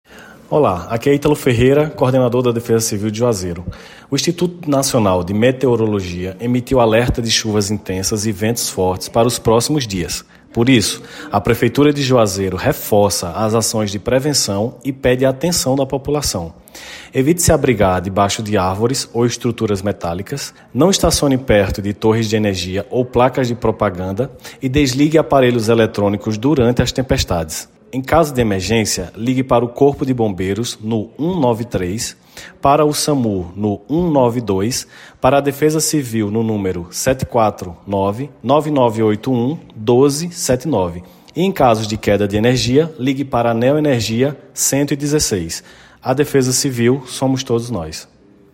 AVISO-DEFESA-CIVIL.mp3